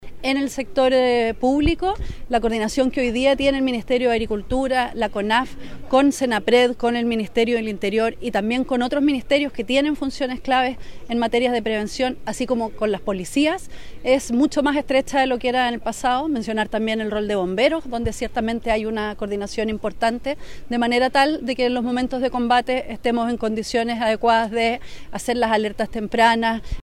Al respecto, la ministra de Agricultura, Ignacia Fernández, dijo que existe una importante coordinación.